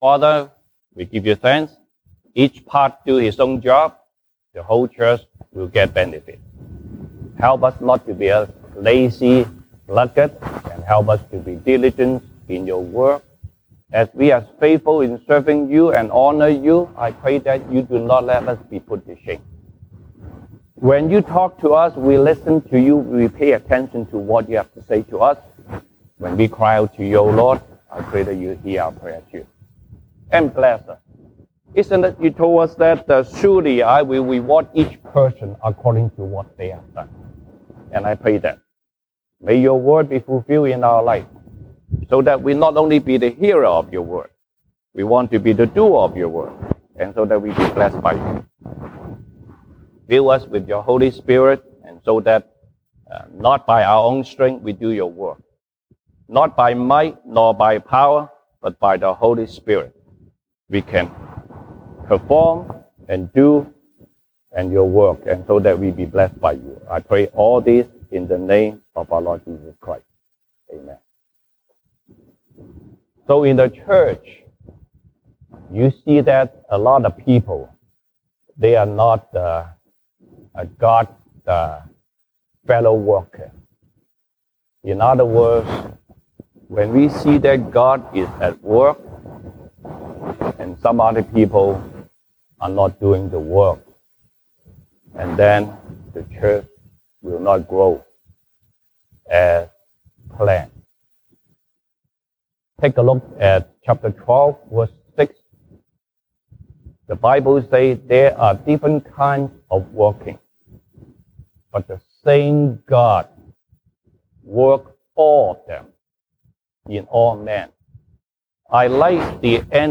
西堂證道 (英語) Sunday Service English: As God’s fellow workers